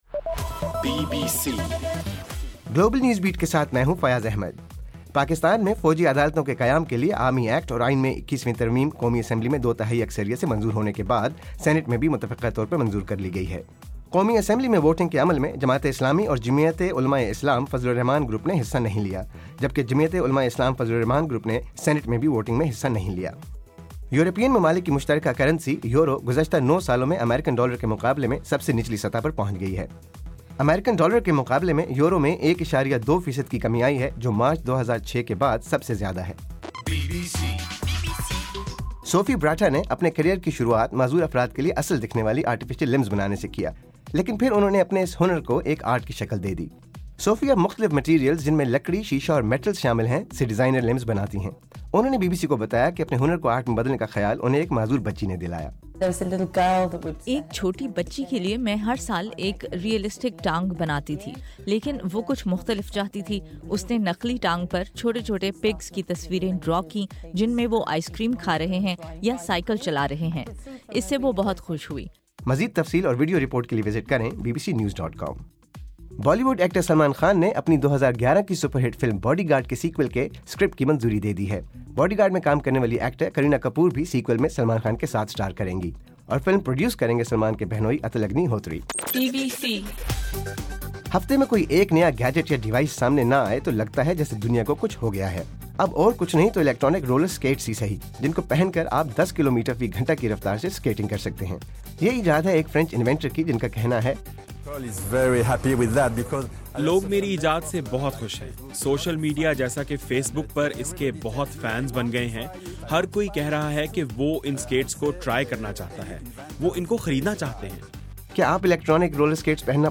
جنوری 6: رات 8 بجے کا گلوبل نیوز بیٹ بُلیٹن